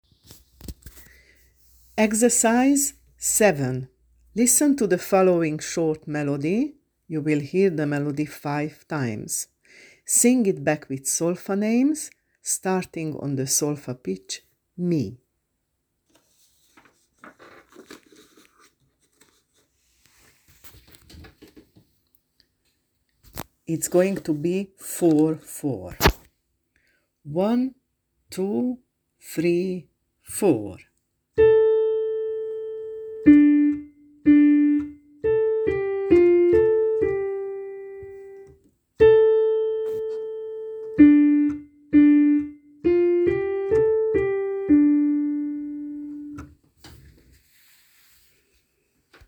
7. Listen to the following short melody (5 times).